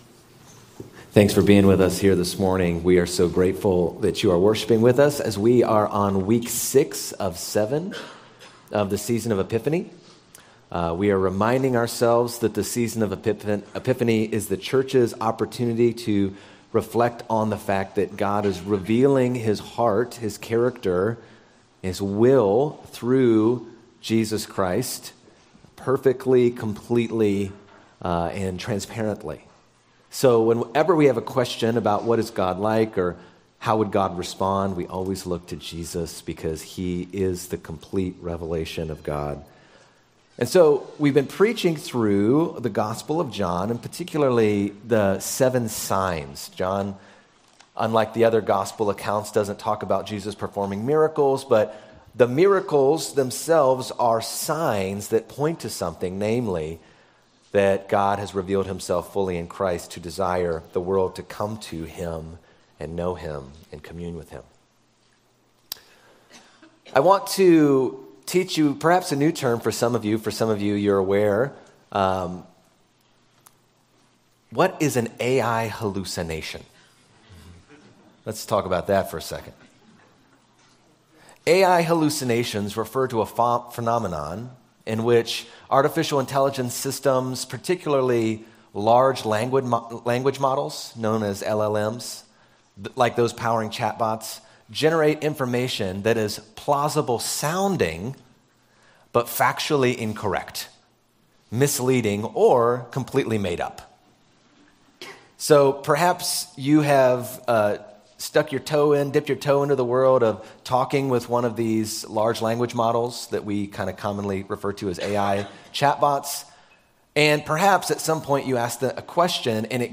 Sermons - Park Street Brethren Church